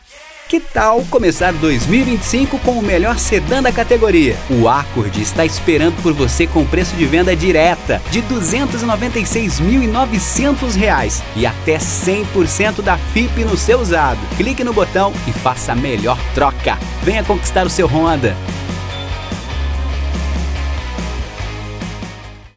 DEMO HONDA :
Padrão
Animada